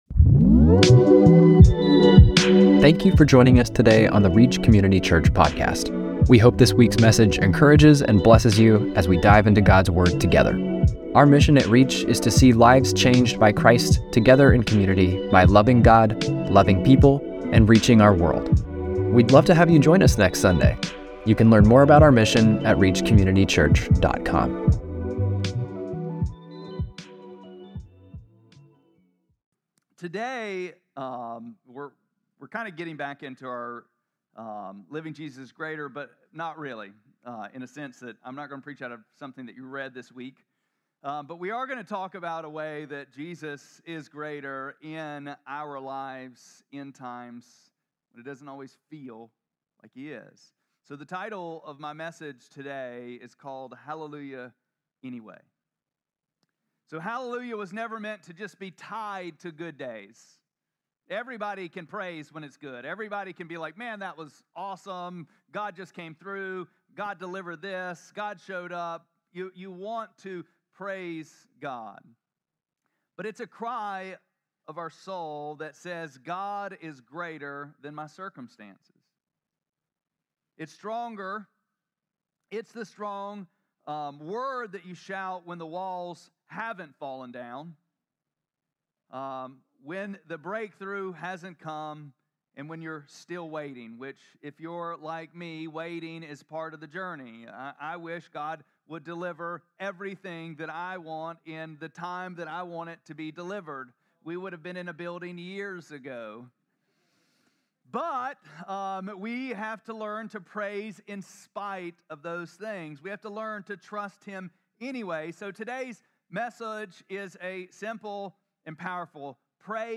4-27-25-Sermon.mp3